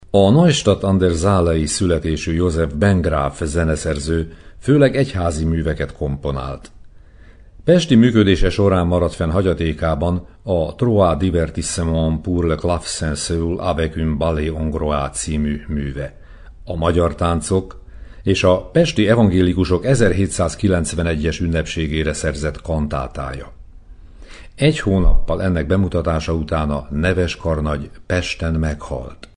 Bengraf Józsefnek a vezetésével katolikus énekkar énekelte a karnagy e célra írt kórusművét, amelynek szövegét a már említett württembergi Werthes professzor írta (22).